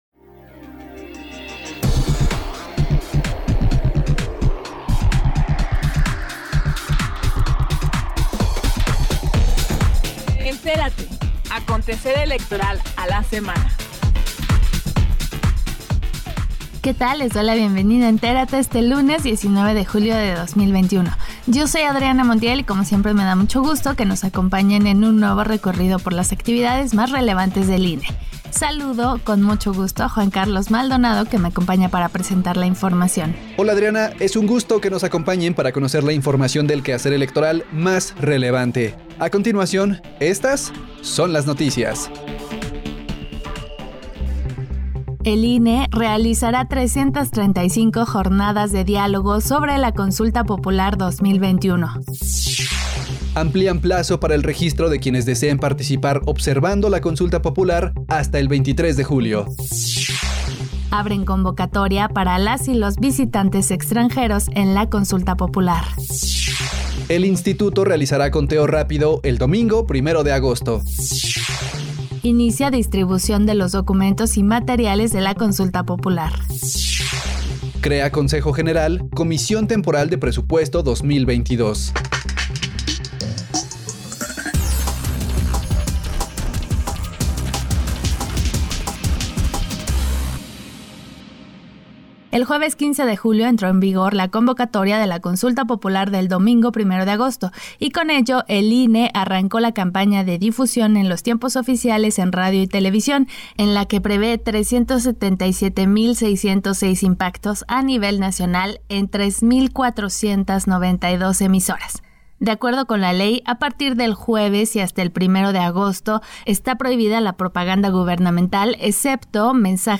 NOTICIARIO 19 DE JULIO 2021